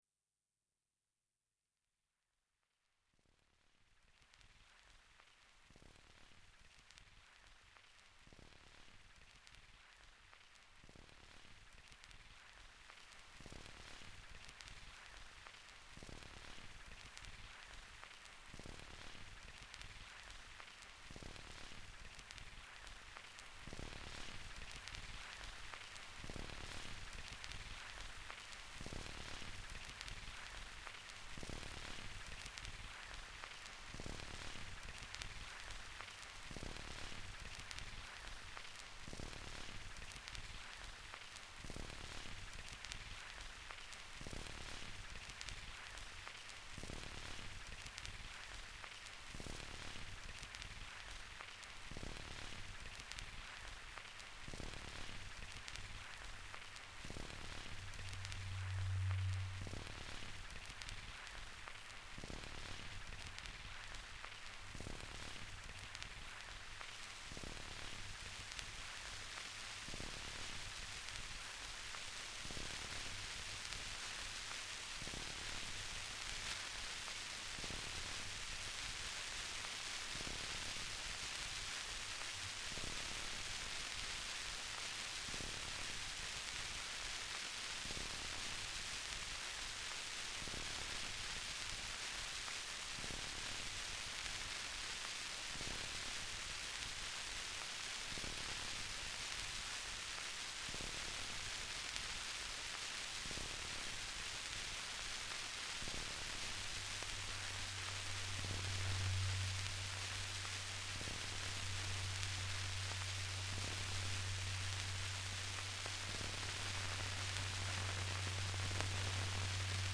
uses three Omnitronic decks
uses two Dancettes and sampler
from desk to hard drive